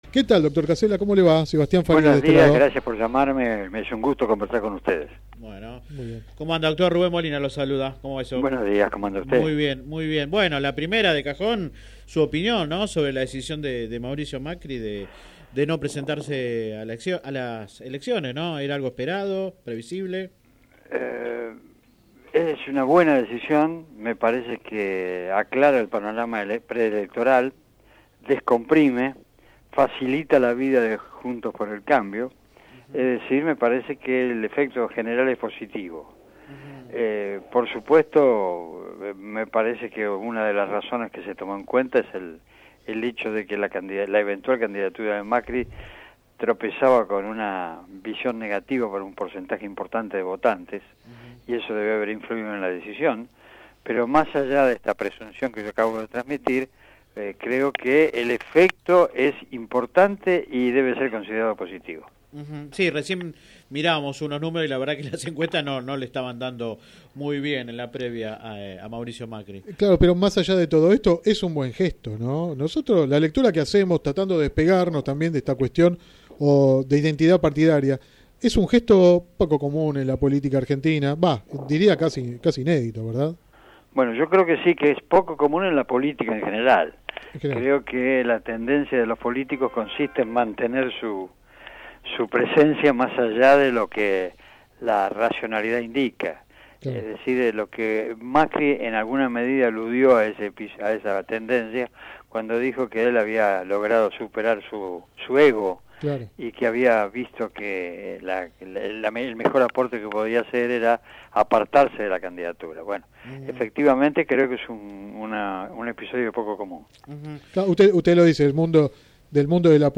El dirigente radical Juan Manuel Casella fue entrevistado en el programa radial Sin Retorno (lunes a viernes de 10 a 13 por GPS El Camino FM 90 .7 y AM 1260). La oportunidad sirvió para hablar sobre el anuncio de Mauricio Macri de no postularse como presidente de la nación.